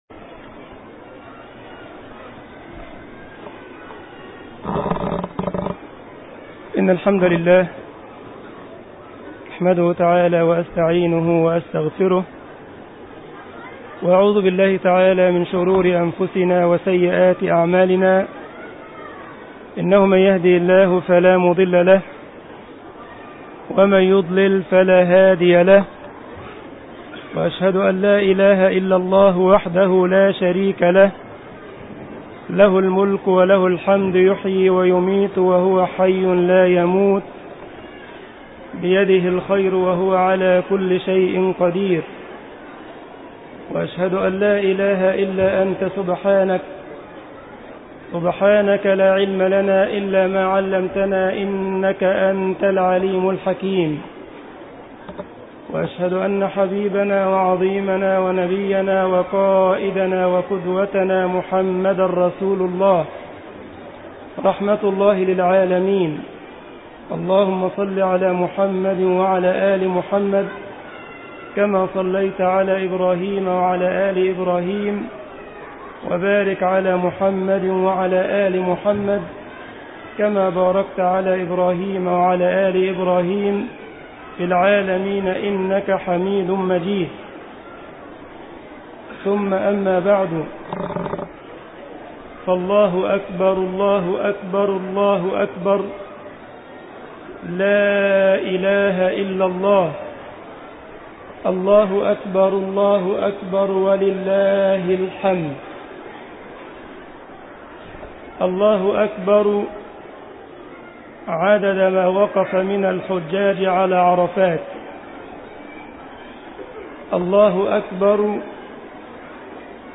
الجمعية الإسلامية بالسارلند ـ ألمانيا خطبة العيد